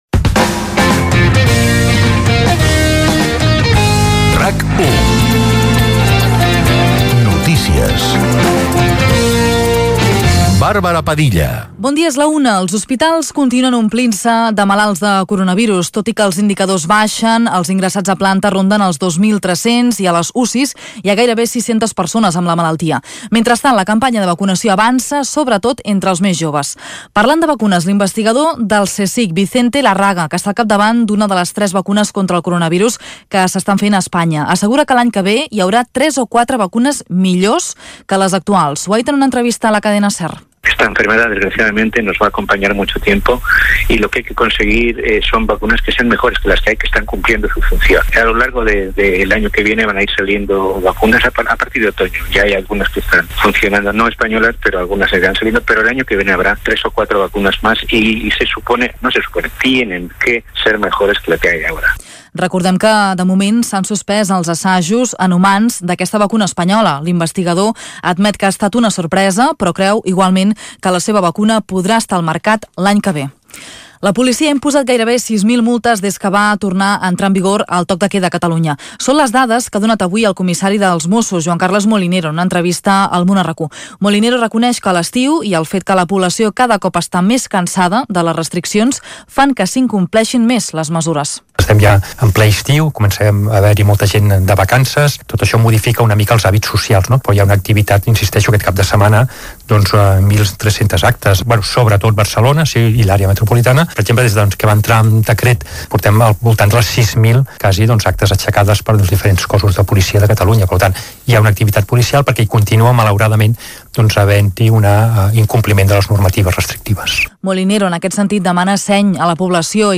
Notícies: butlletí informatiu de les 13h - RAC1, 2021